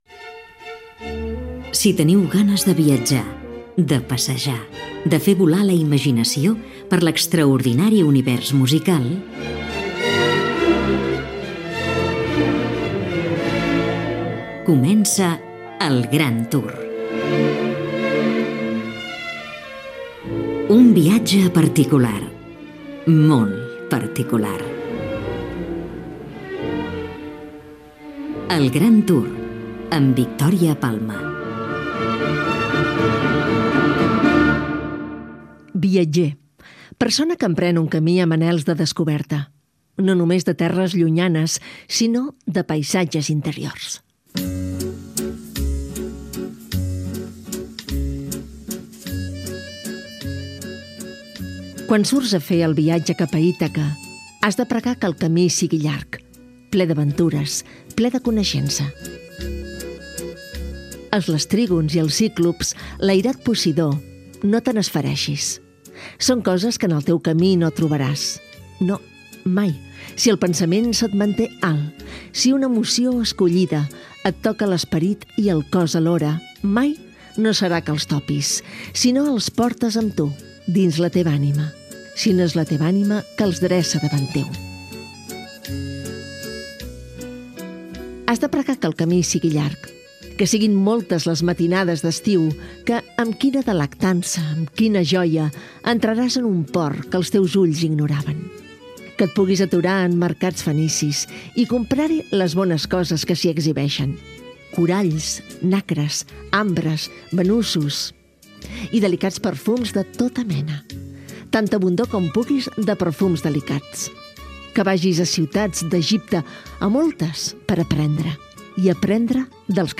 Careta i inici del programa. Fragments del poema "Viatge a Ítaca". Presentació del tema musical
Musical